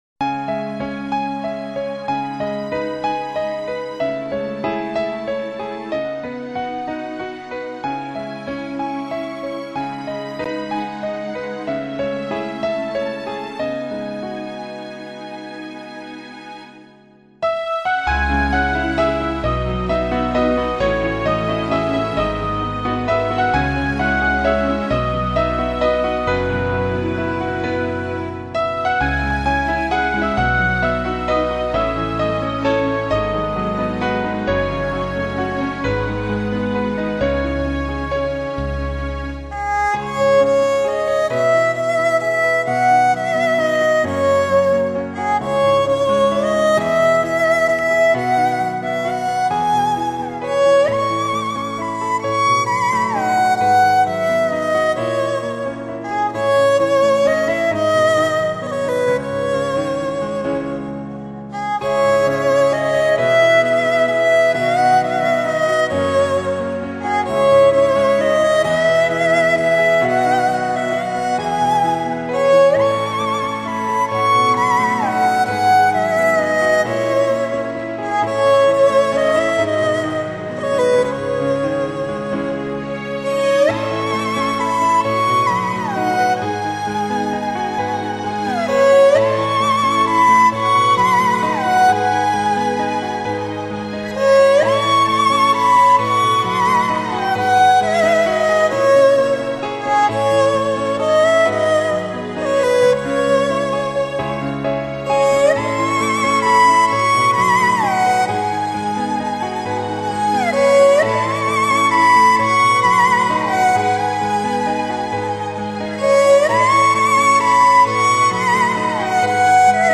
light]纯音乐